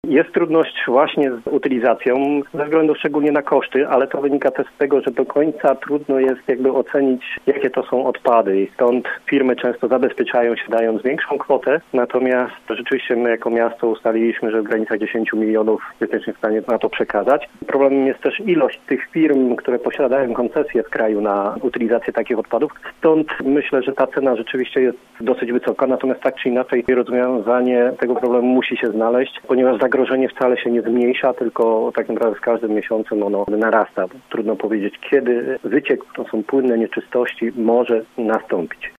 Piotr Barczak był gościem Rozmowy po 9.